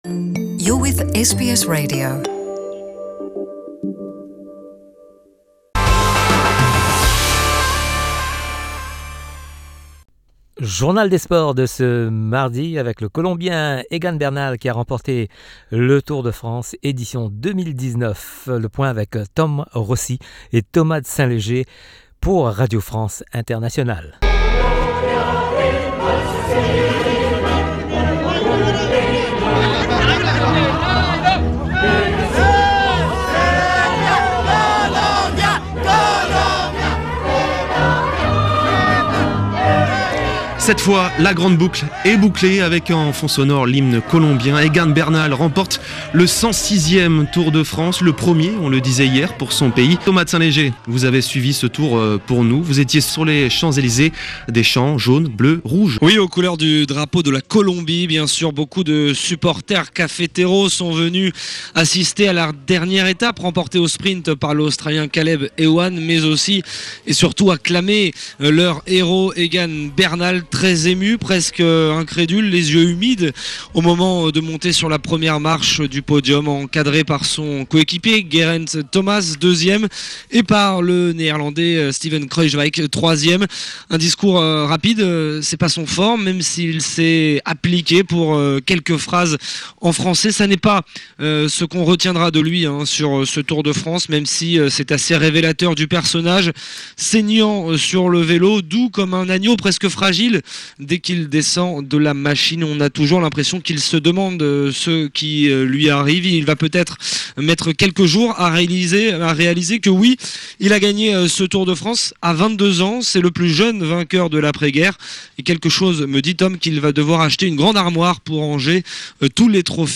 Le journal des sports du 30 juillet.
L’actualité sportive avec les sonores de RFI.